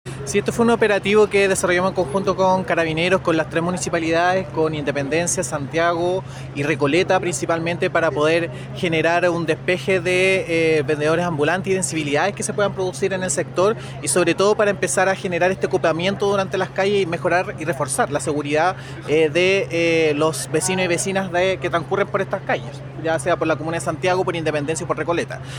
El delegado presidencial (s) de la RM, Jaime Fuentes, entregó más detalles sobre el operativo e indicó que el trabajo en conjunto se fortalecerá para prevenir y erradicar este tipo de situaciones en las tres comunas.